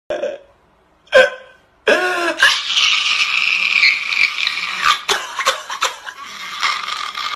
crying meme Meme Sound Effect
Category: Reactions Soundboard
crying meme.mp3